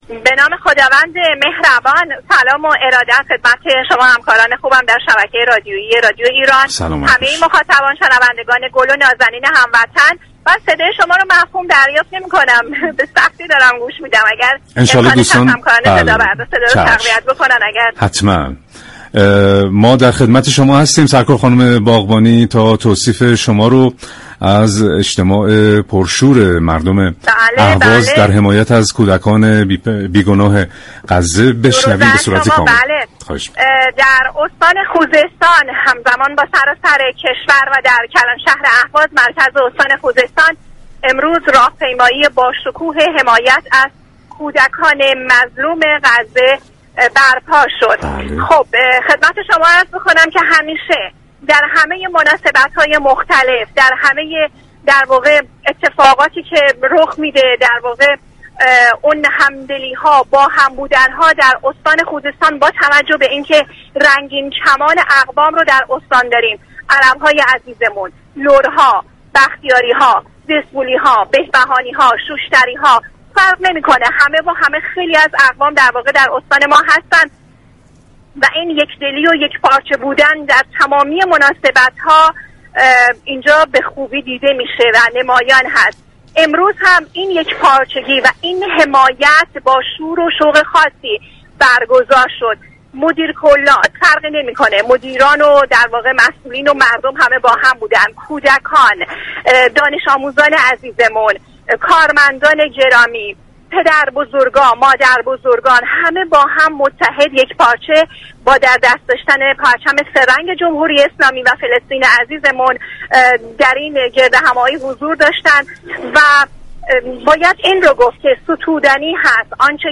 ایرانیاران از شهرهای مختلف ایران از حضور گسترده مردم جهت حمایت از مردم فلسطین به رادیو ایران ویژه برنامه «این خشم مقدس» گزارش می دهند.